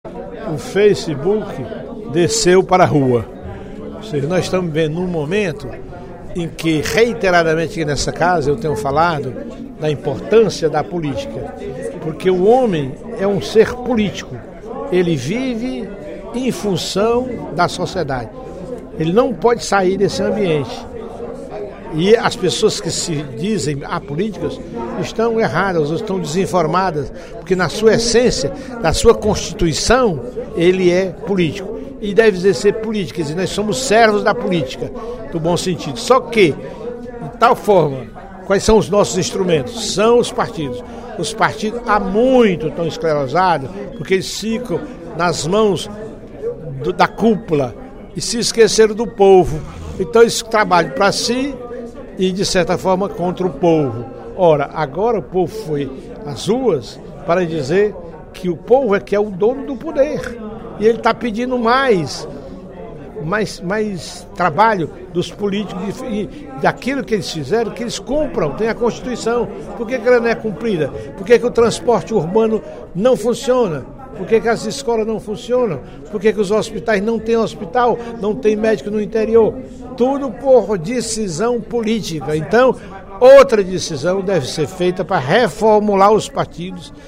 Durante o primeiro expediente desta terça-feira (02/07), o deputado Professor Teodoro (PSD) falou sobre a importância da política na vida dos cidadãos, especialmente no atual contexto brasileiro, que conta com o reforço das mobilizações feitas por meio da internet.
Em aparte, o deputado João Jaime (PSDB) também criticou a vinda de médicos estrangeiros e afirmou que a proposta não é suficiente porque também faltam medicamentos.